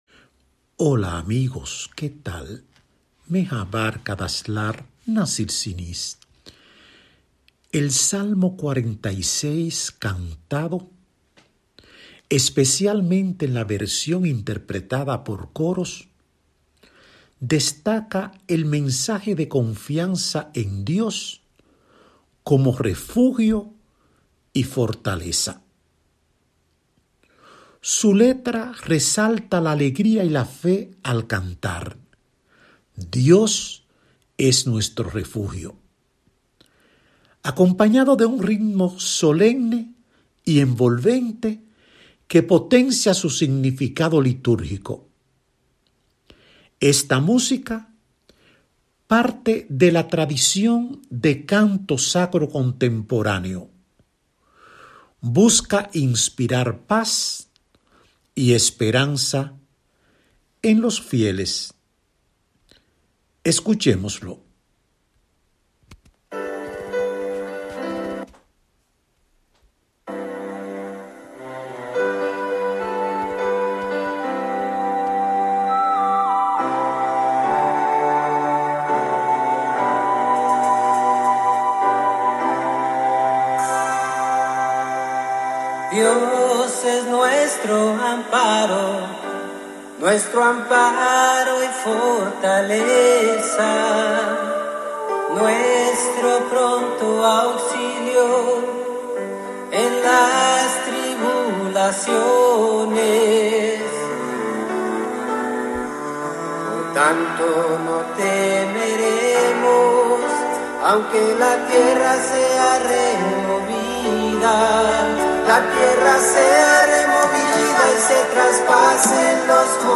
REFLEXIONESEl Salmo 46 cantado, especialmente en la versión interpretada por Coros, destaca el mensaje de confianza en Dios como refugio y fortaleza. Su letra resalta la alegría y la fe al cantar: «Dios es nuestro refugio», acompañado de un ritmo solemne y envolvente que potencia su significado litúrgico.
Esta música, parte de la tradición de canto sacro contemporáneo, busca inspirar paz y esperanza en los fieles.